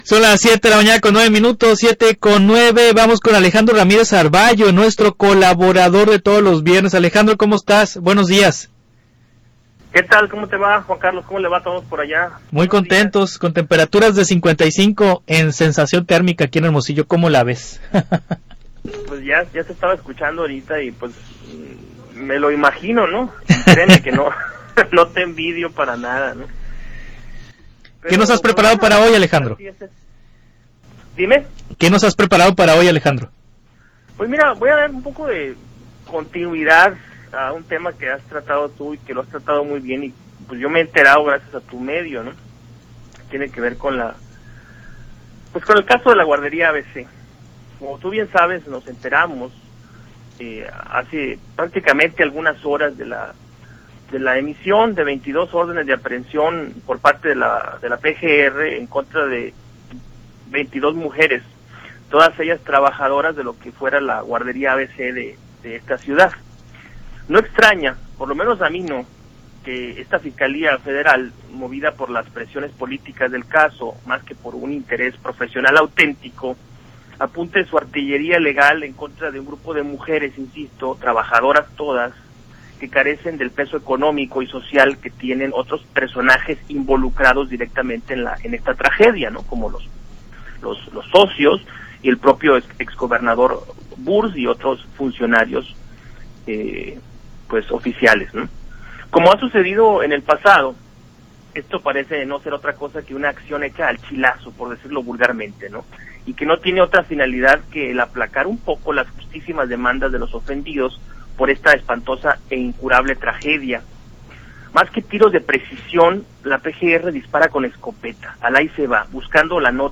Transmisión en radio